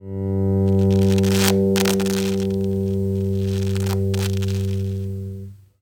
E-Bass Poti defekt
Aufgrund der oft schlechten Qualität der verbauten Potentimeter in E-Instrumenten muss man meist schon bald diese Tauschen. Wir hören die Auswirkungen des defekten/verunreinigten Treble-Regler meines E-Basses.